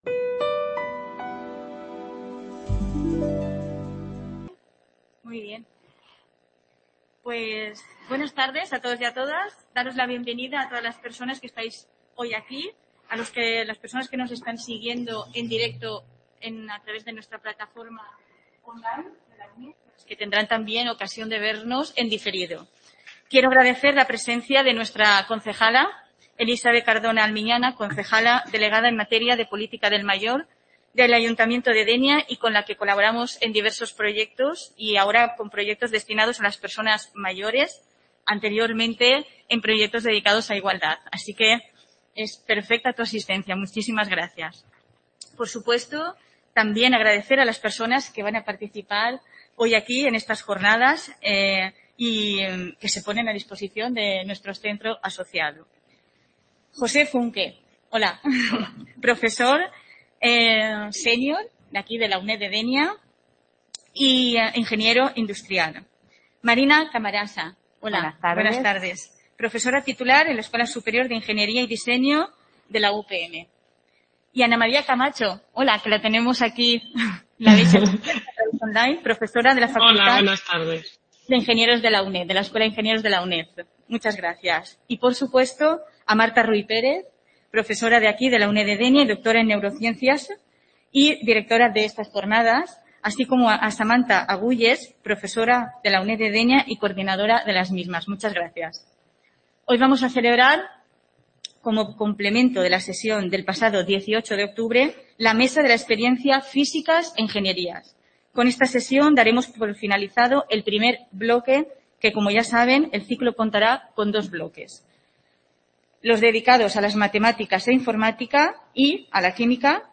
Mientras que en las Mesas de la experiencia, serán mujeres mayoritariamente, y hombres, cuyo recorrido vital y profesional sea más extenso y puedan aportar su trayectoria profesional como ejemplo de superación, así como también las dificultades con las que han tenido que encontrarse.